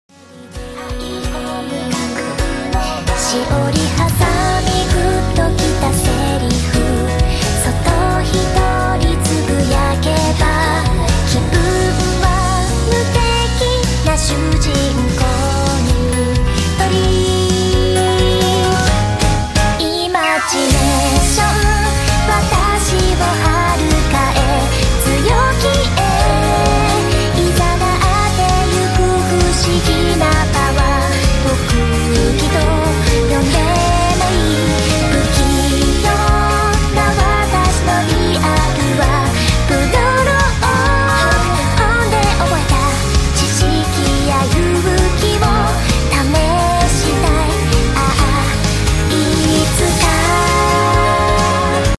i love how soft her voice is aaaaaaahhhh!!!!